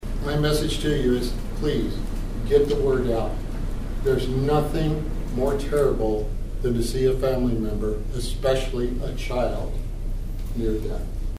The Kansas Department of Transportation officially launched the statewide “Click It or Ticket” campaign Wednesday at Manhattan’s Peace Memorial Auditorium.